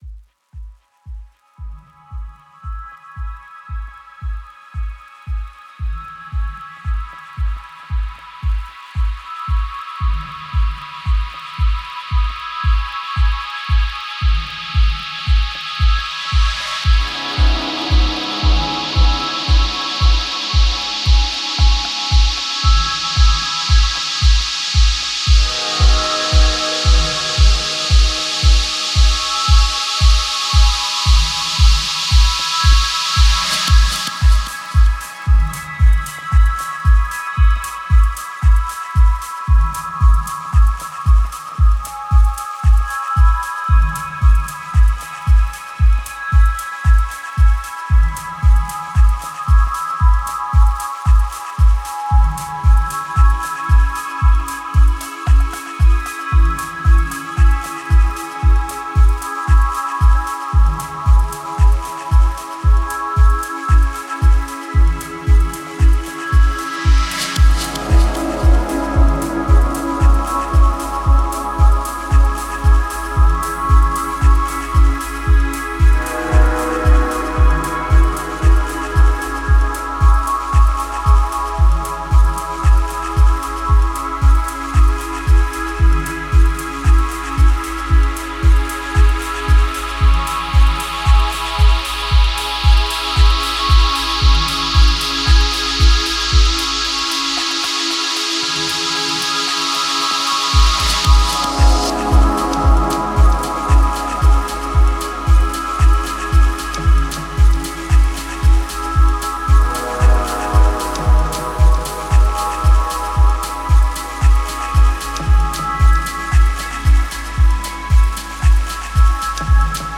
Genre: Dub Techno/Techno.